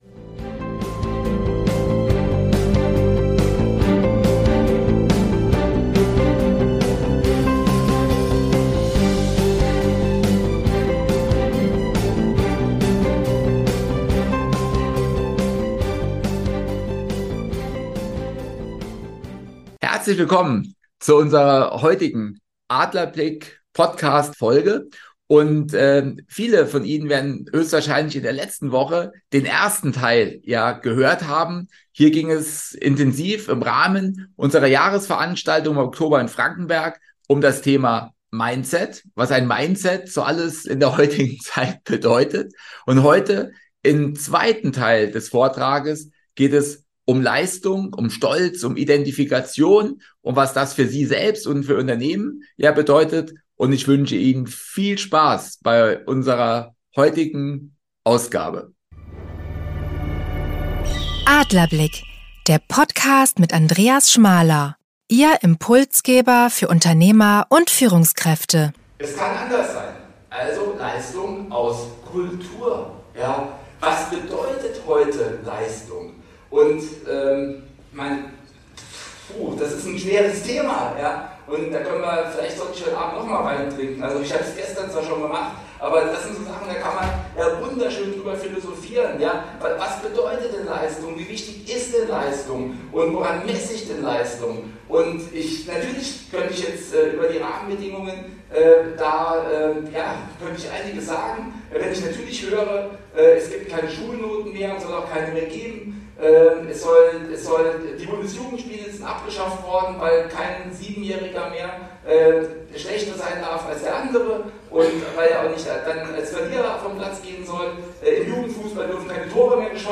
Teil seines Impulsvortrages.